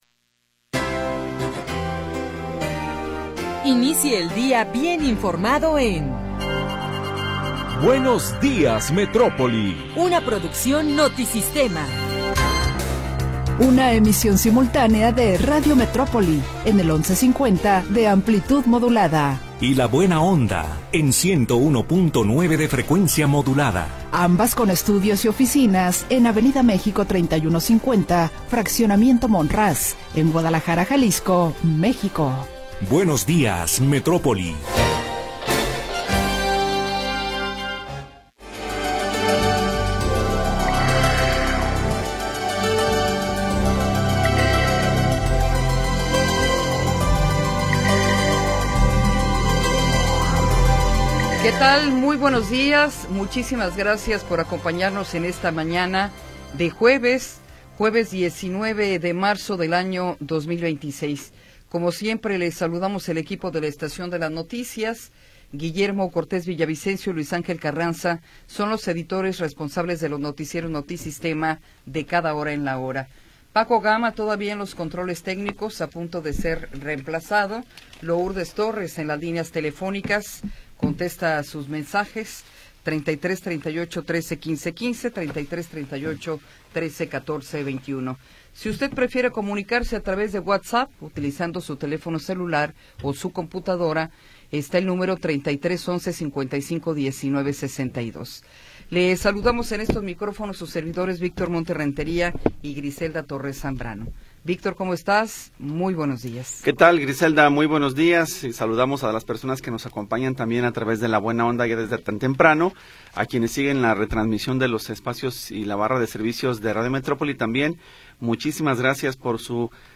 Primera hora del programa transmitido el 19 de Marzo de 2026.